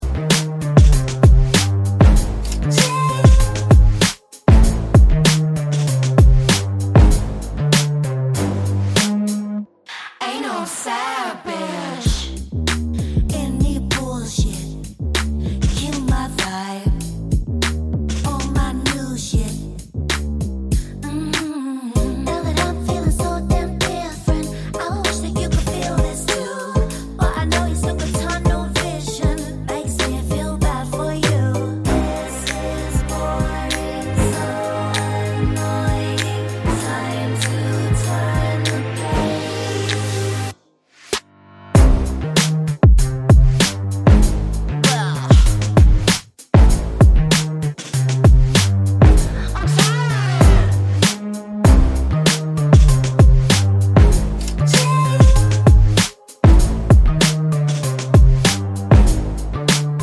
Clean Backing Vocals Pop (2020s) 2:14 Buy £1.50